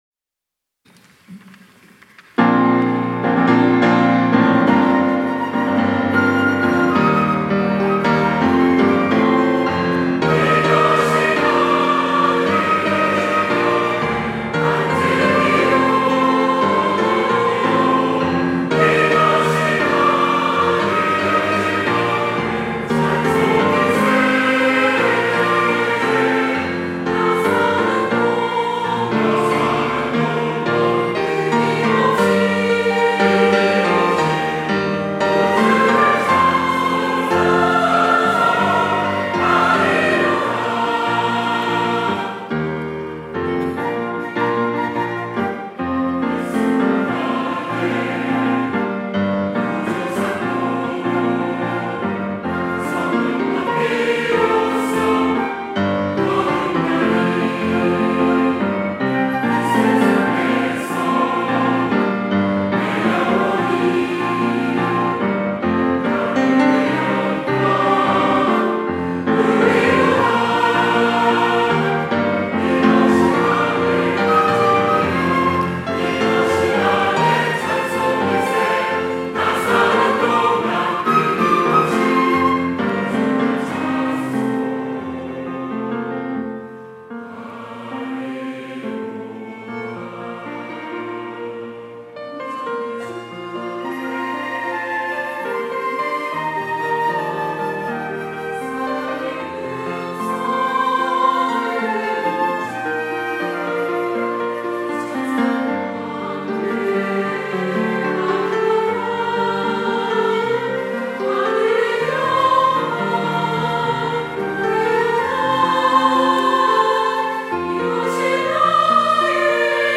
특송과 특주 - 이것이 나의 간증이요
천안중앙교회 찬양대